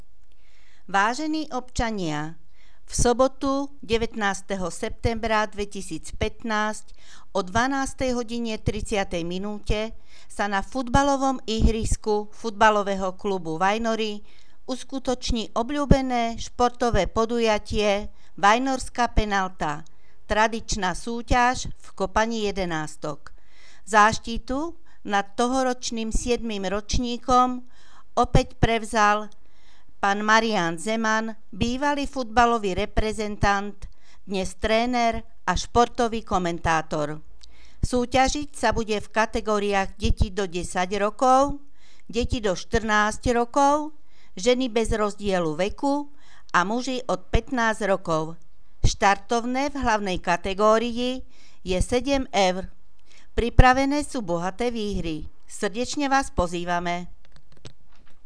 Hlásenie miestneho rozhlasu 18.9.2015